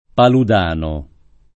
palud#no] cogn. — nome latineggiante di vari personaggi dei secoli passati, trad. di cognomi che nelle lingue d’origine equivalgono a «Della Palude» (fr. De la Palu, neerl. Van den Broek)